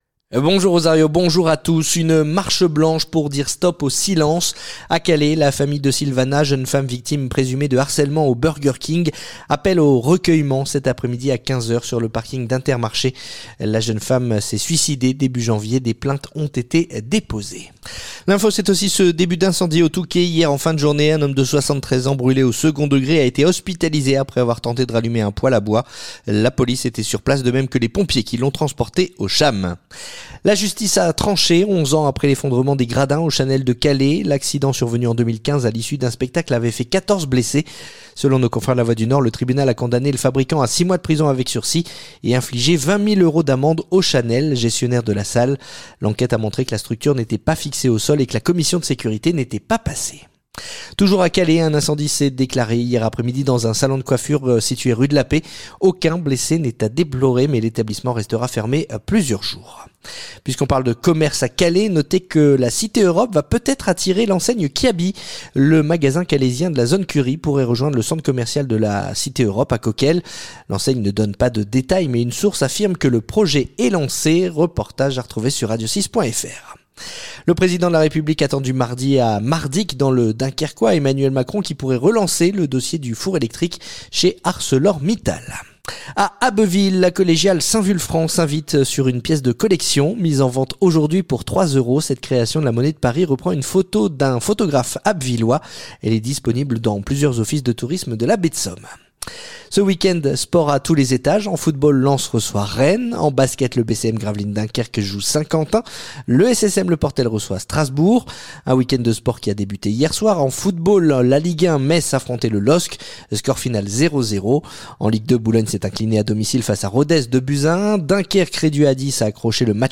Le journal du samedi 7 février